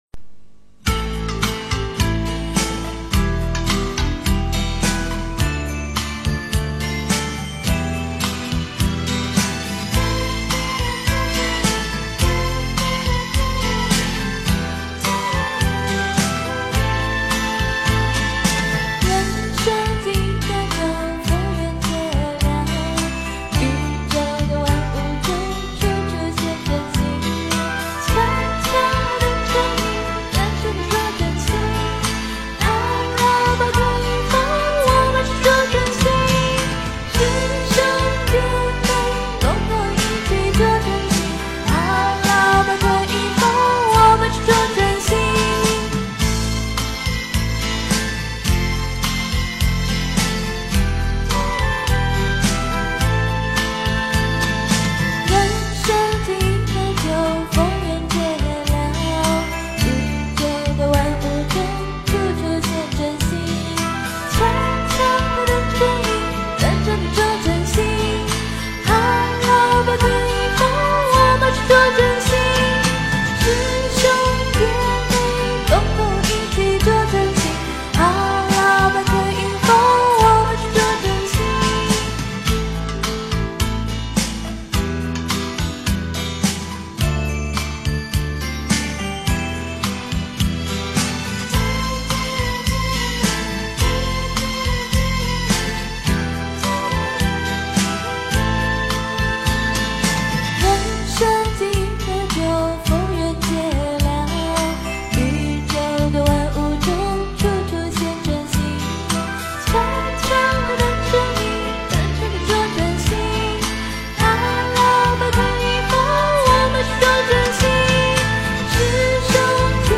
捉真性 诵经 捉真性--佛教音乐 点我： 标签: 佛音 诵经 佛教音乐 返回列表 上一篇： 种法 下一篇： 自在修行 相关文章 夫妻与因果--有声佛书 夫妻与因果--有声佛书...